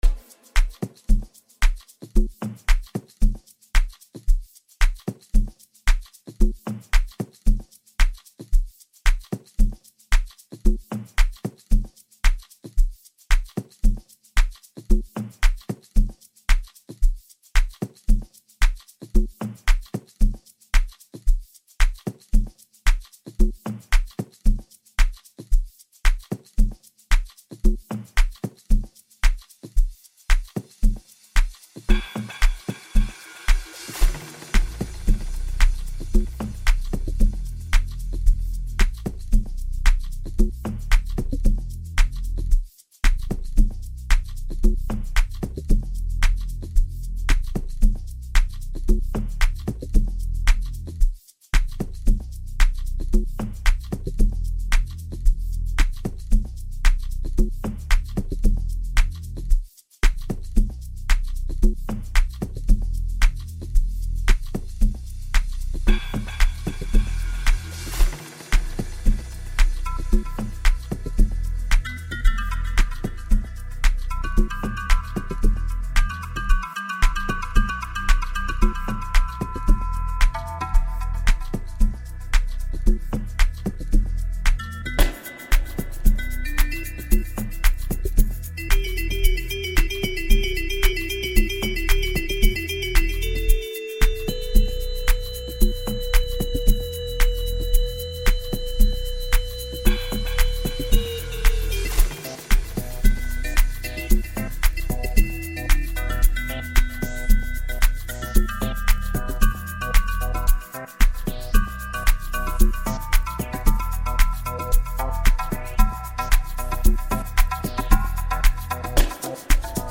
heavy percussions and powerful bass